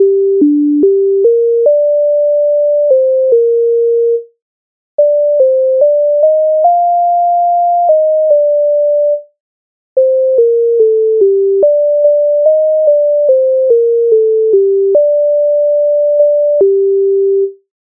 MIDI файл завантажено в тональності g-moll
Налетіли журавлі Українська народна пісня з обробок Леонтовича с. 112